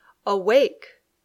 Pronunciación